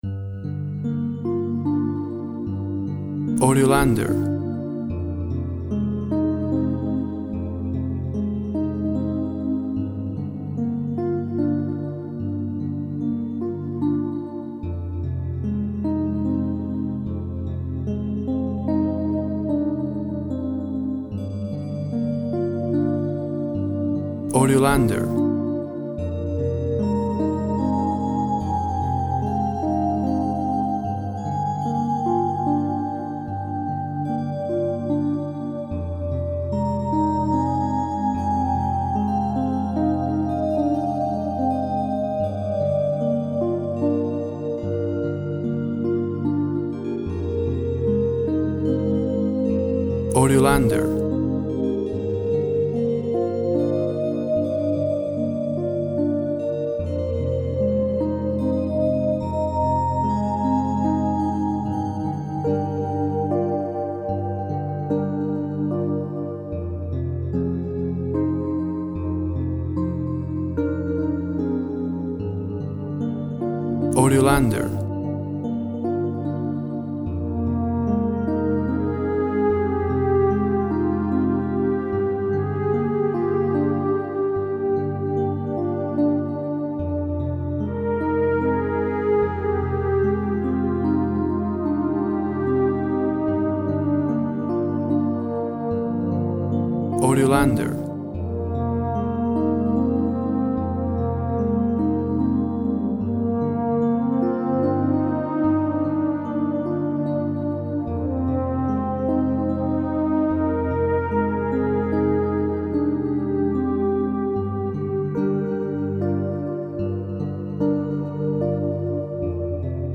Lush dreamy sounds of harp, horn, and synth strings.
key Gmi
Tempo (BPM) 64